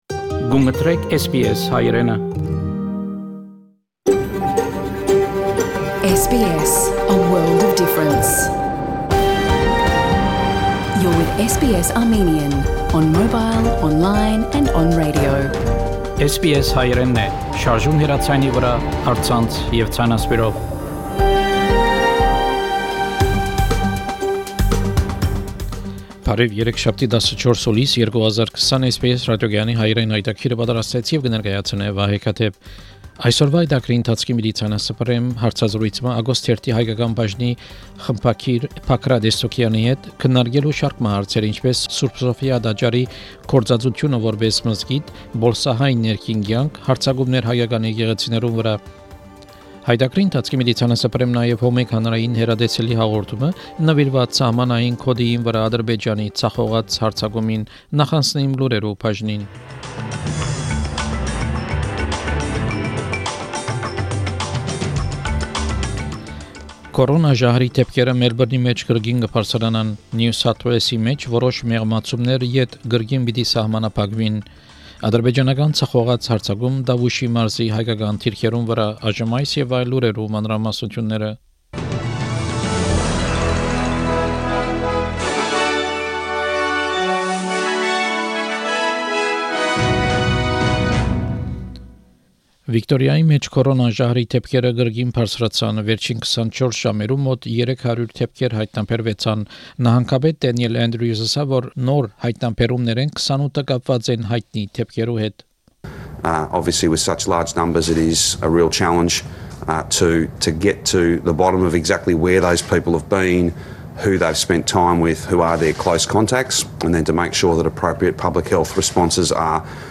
SBS Armenian news bulletin – 14 July 2020
SBS Armenian news bulletin from 14 July 2020 program.